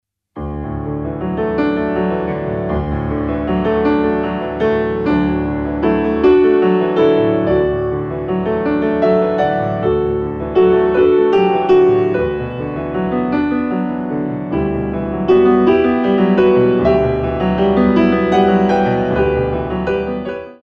6/8 - 8x8